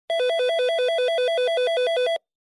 Звуки домофона
Здесь вы найдете как стандартные сигналы вызова, так и редкие варианты – от коротких гудков до мелодичных оповещений.